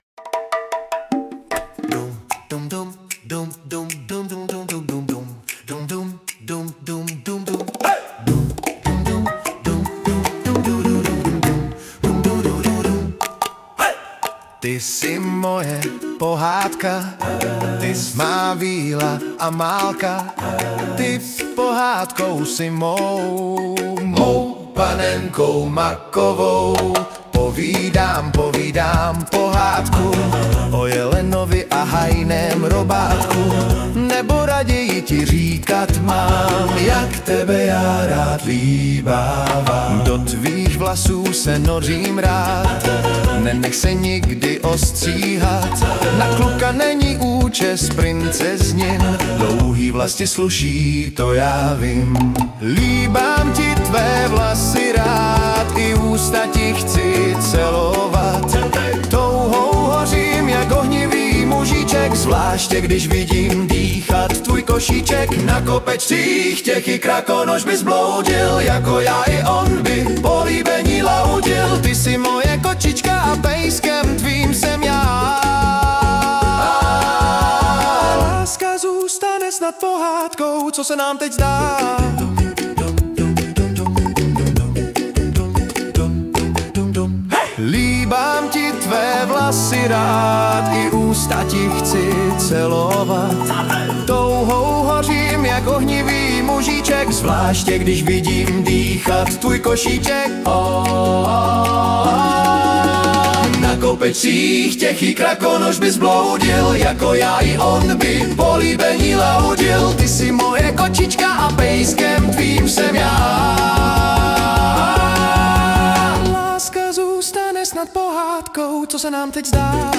hudba, zpěv: AI